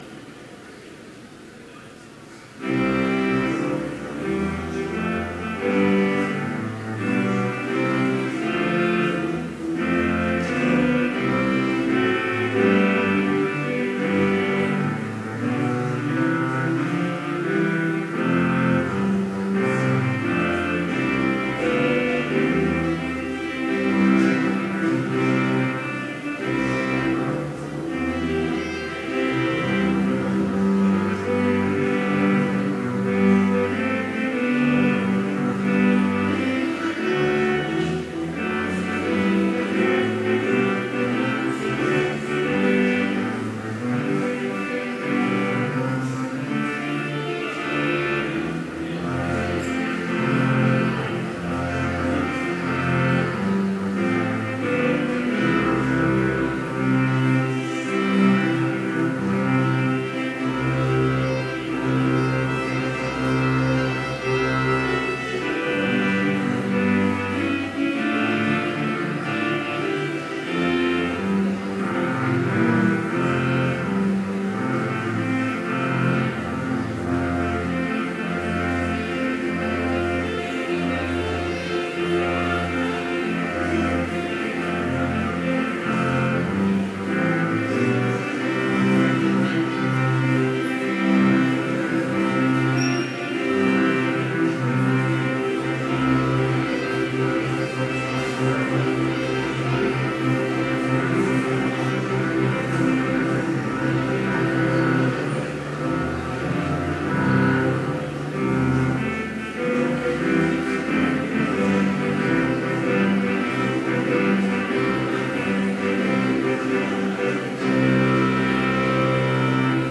Chapel worship service held on October 16, 2017, BLC Trinity Chapel, Mankato, Minnesota
Complete service audio for Chapel - October 16, 2017